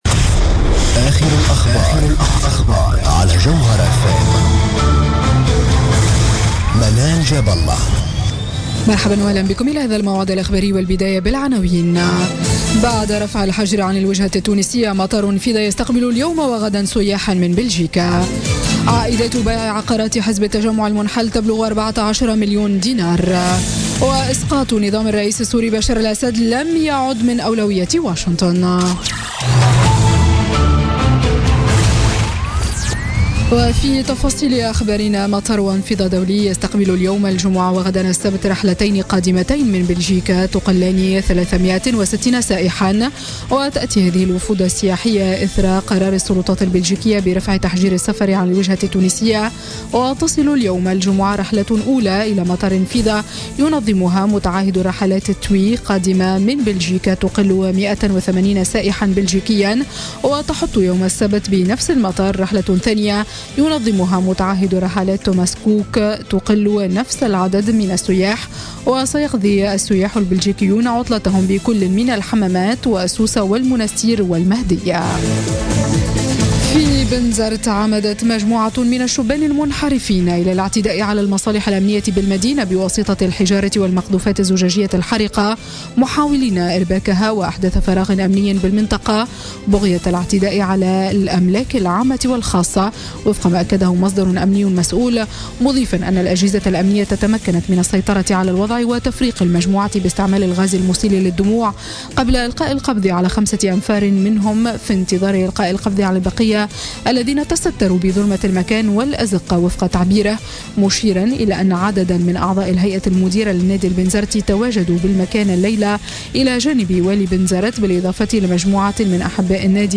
نشرة أخبار منتصف الليل ليوم الجمعة 31 مارس 2017